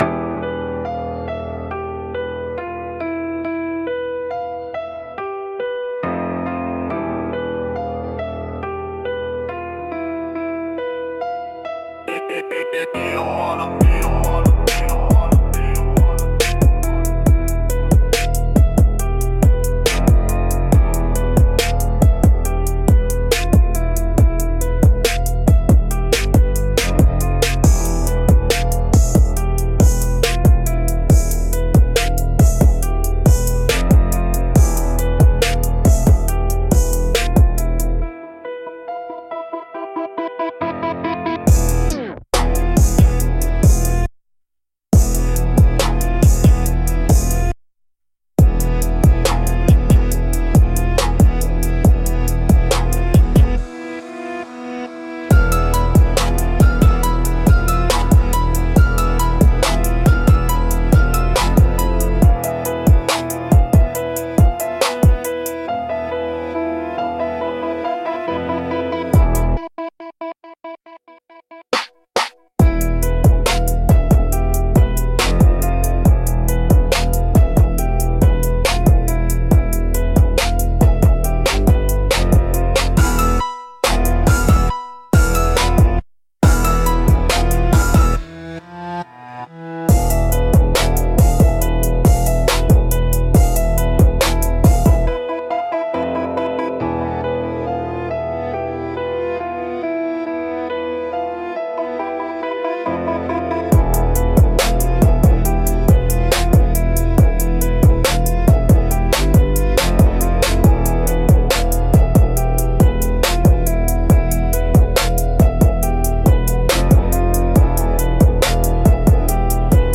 Instrumentals - Iron Clad Groove